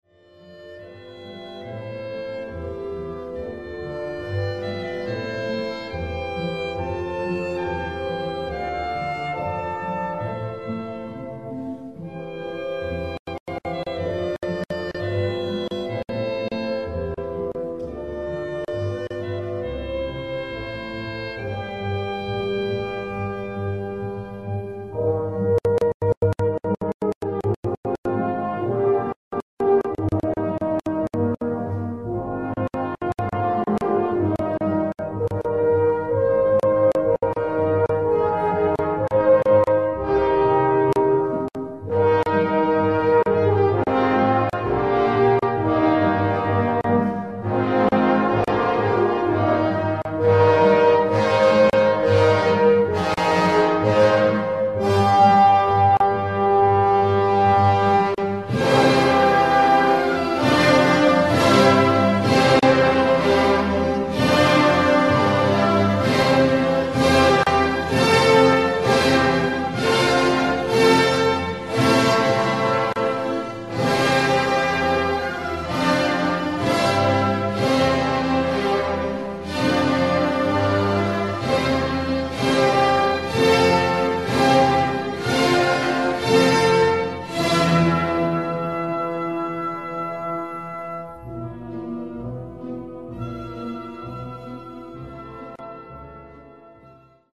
La obra orquestal y operística de Borodin, importante compositor ruso del siglo XIX, causa impresión espectacular, es brillante e irresistiblemente bárbara al estilo del exotismo de la música romántica.